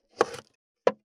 565 – EFFECT-CUE
効果音